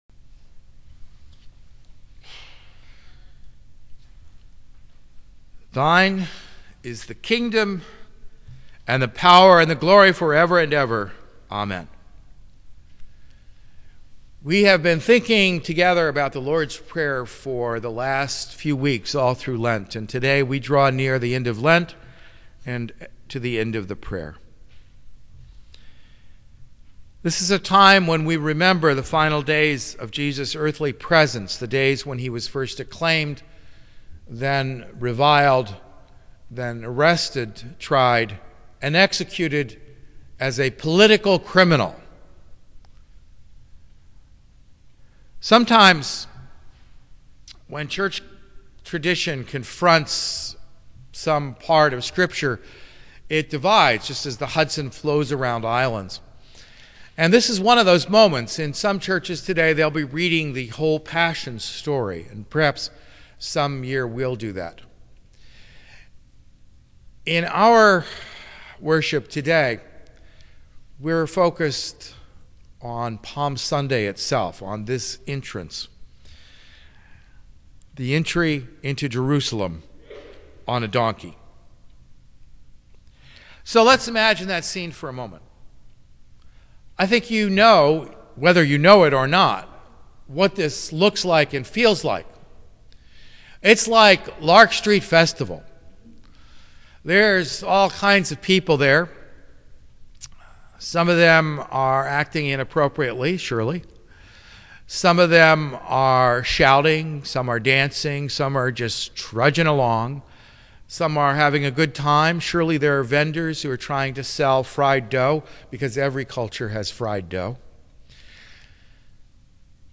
A Sermon for the First Congregational Church of Albany, NY